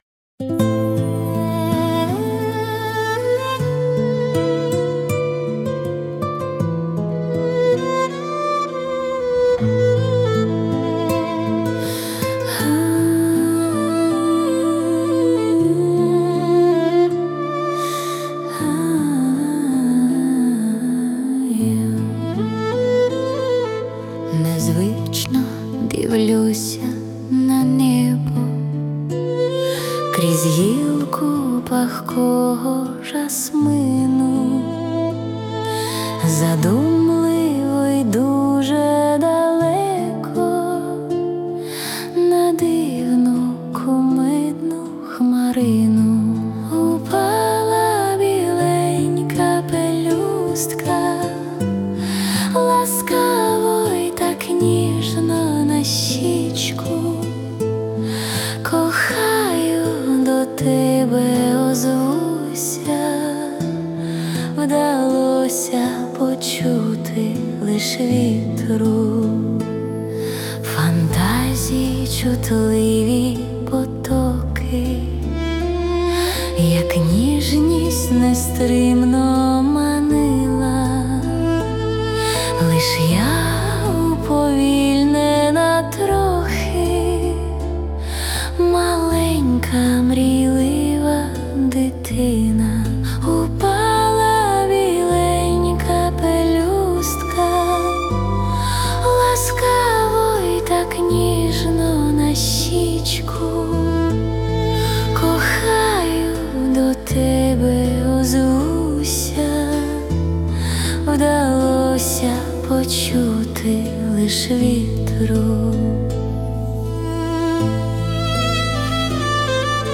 Музична композиція створена за допомогою SUNO AI
СТИЛЬОВІ ЖАНРИ: Ліричний
Чарівний романс flo06 flo17 flo21 flo17 flo06